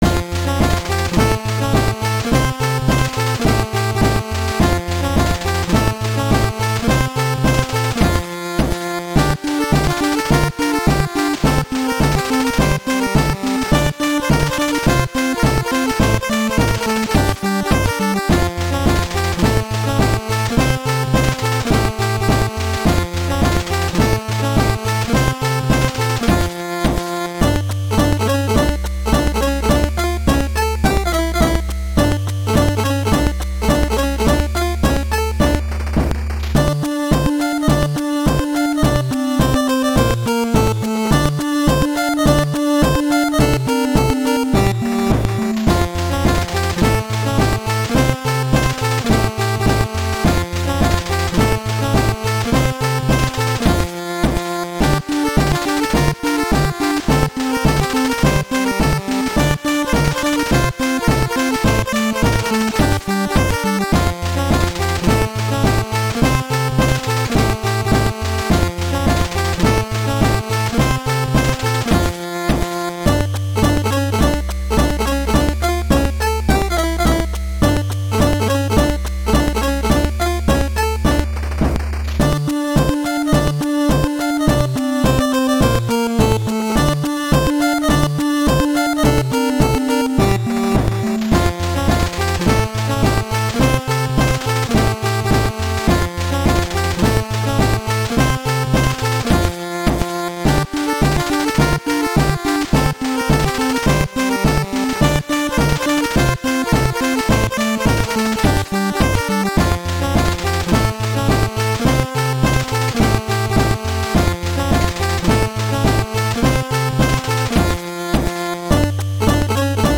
March of The Preobrazhensky Regiment (NES Remix)
A (Bad) NES Remix of the traditional Russian march...On request I will provide nsf, 0cc, wav...